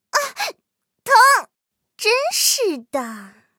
M10狼獾小破语音2.OGG